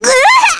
Rephy-Vox_Damage_kr_02.wav